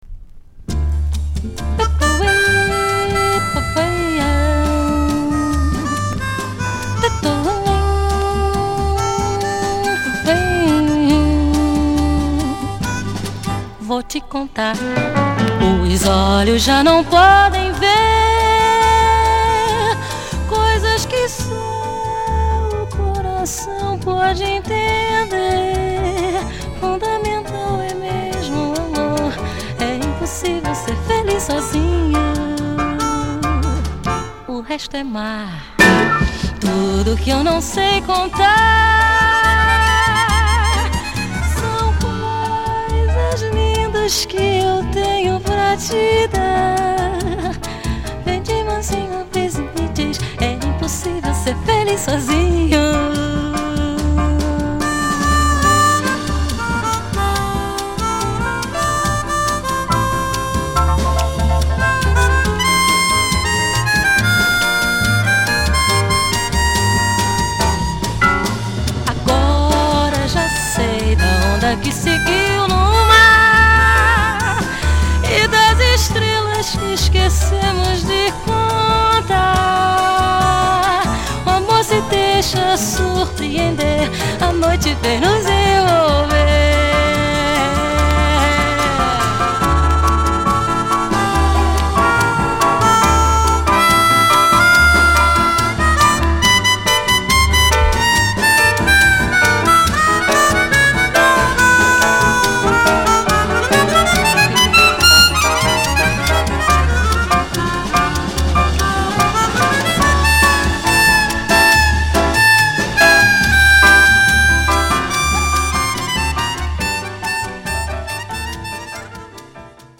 Jazz / Other sweden
1969年にスウェーデンで録音された